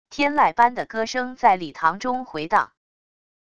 天籁般的歌声在礼堂中回荡wav音频